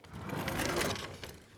Cutlery Drawer Open Sound
household